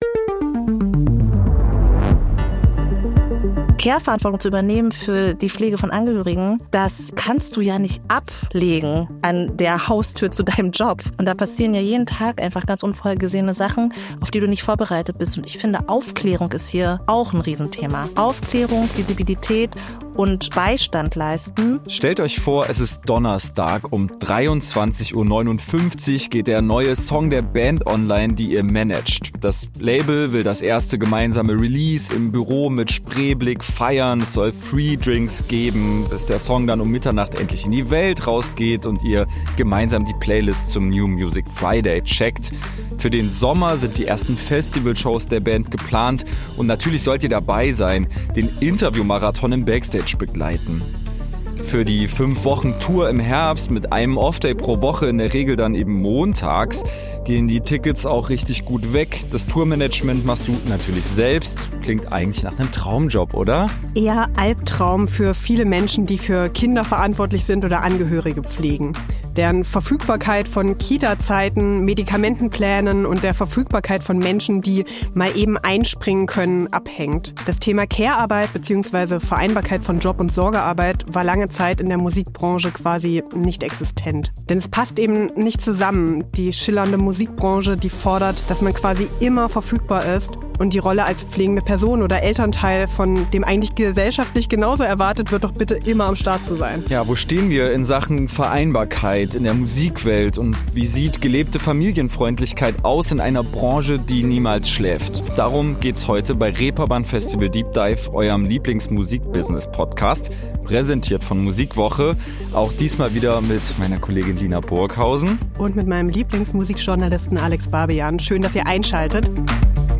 Studio: German Wahnsinn